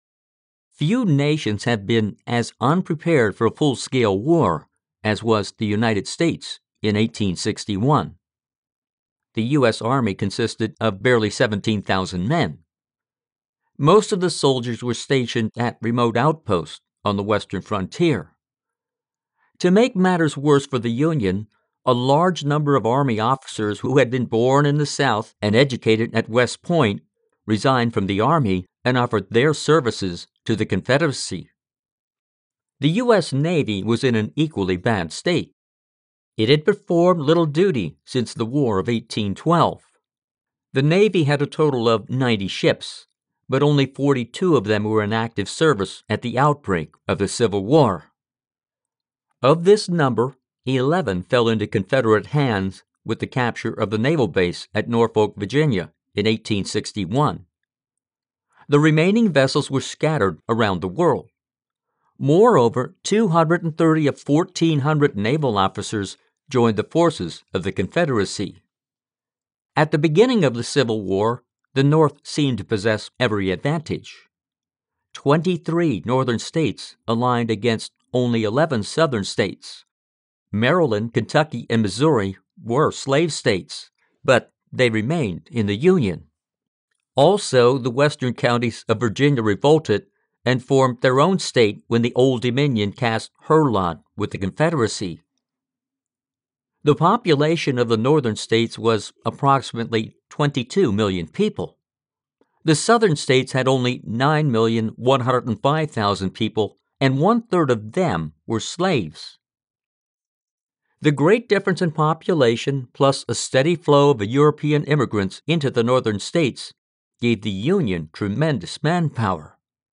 Children's and adult audiobooks